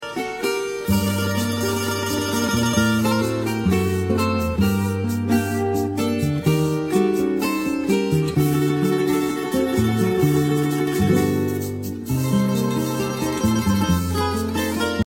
CLASES DE CUATRO PUERTORRIQUEÑO GRATIS